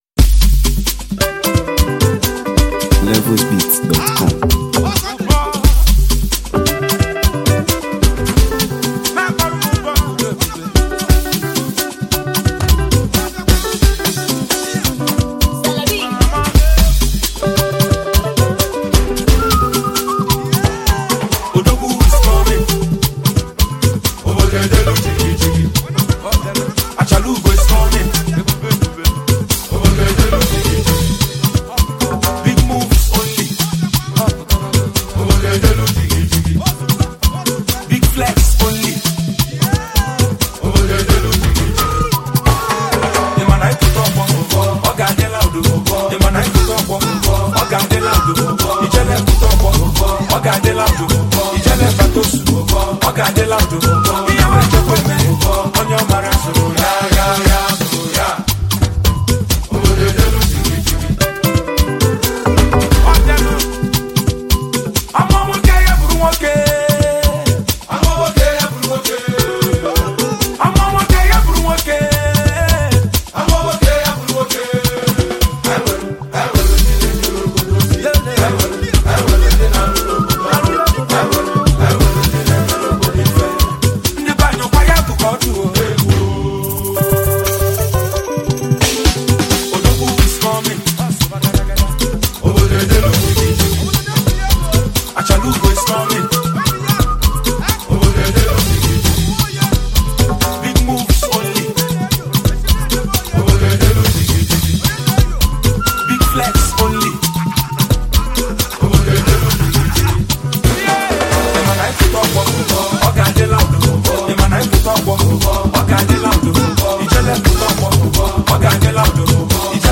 The song’s infectious rhythm, vibrant instrumentation
With its catchy hook, pulsating beats, and inspiring message